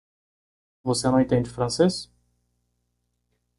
Pronounced as (IPA)
/fɾɐ̃ˈse(j)s/